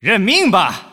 技能语音